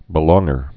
(bĭ-lôngər, -lŏng-)